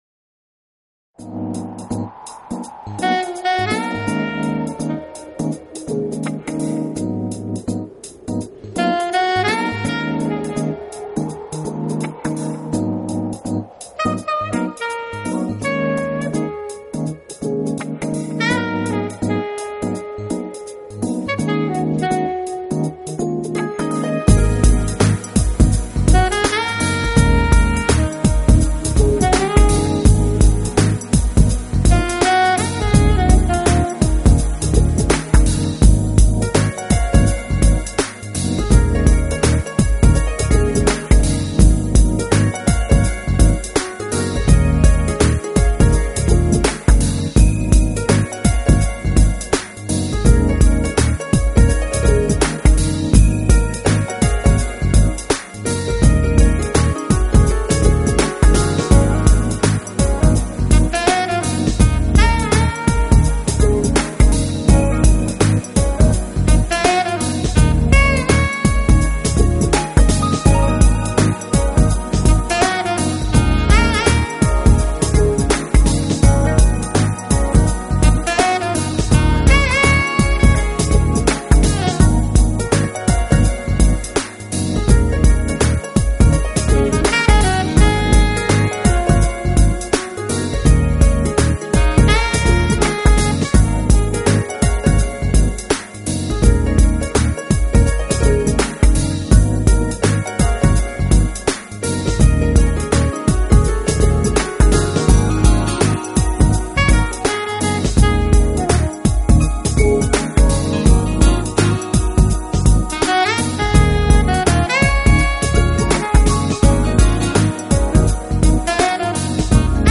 旋律轻柔流畅，节奏舒缓时尚，器乐创新搭